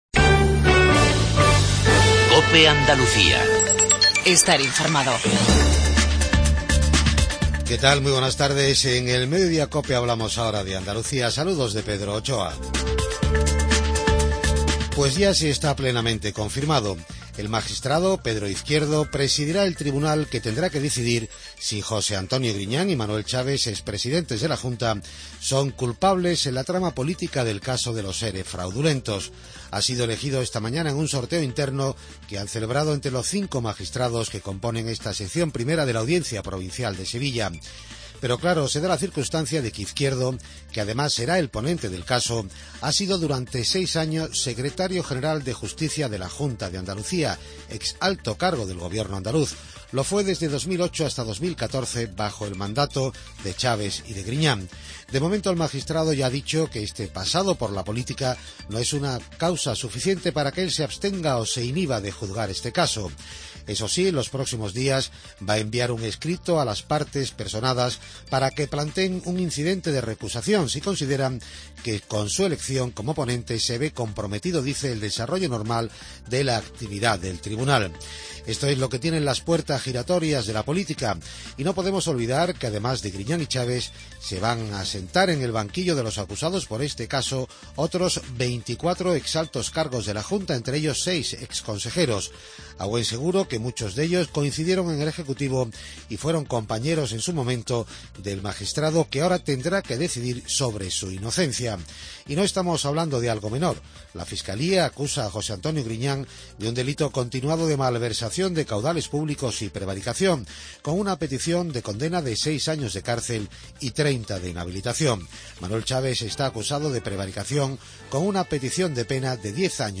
INFORMATIVO REGIONAL MEDIODIA